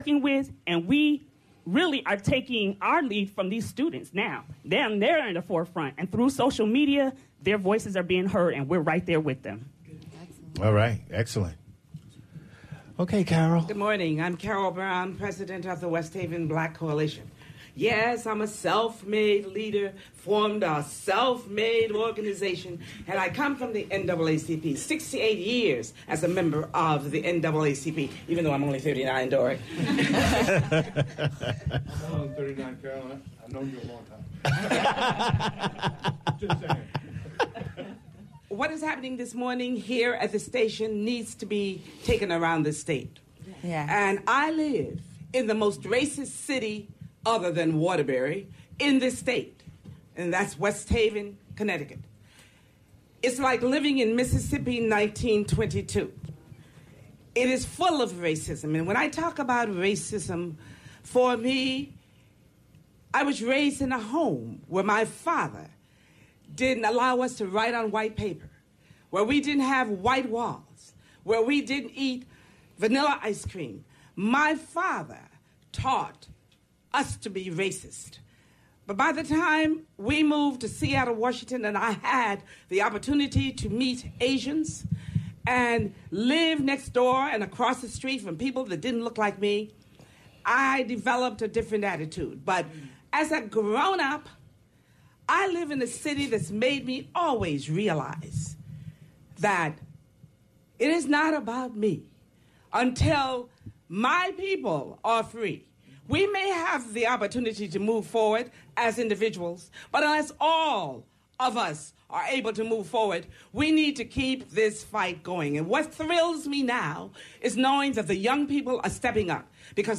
LIVE ROUNDTABLE DISCUSSION 11.21.15 10a pt 2